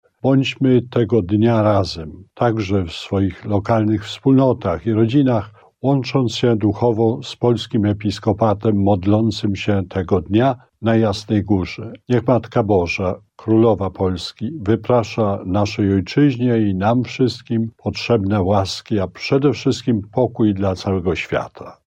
Mówi abp Stanisław Gądecki, przewodniczący Konferencji Episkopatu Polski.